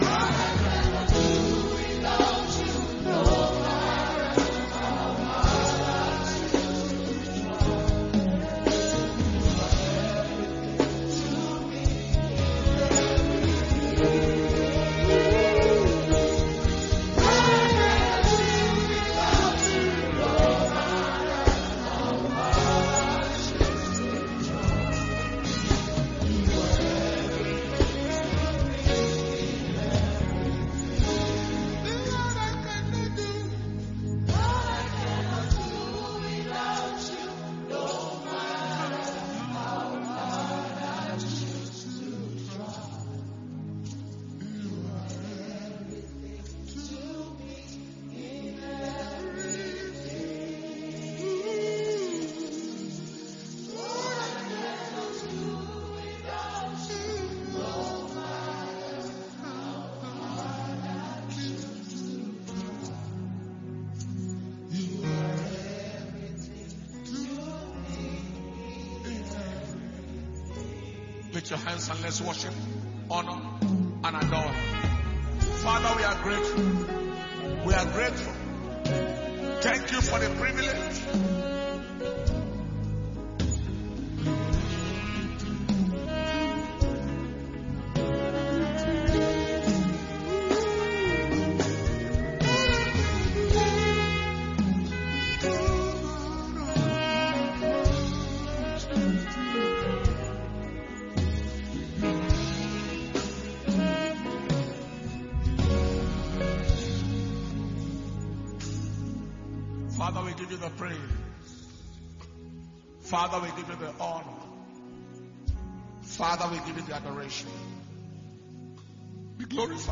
New Year Service/Blessing Sunday Service
Messages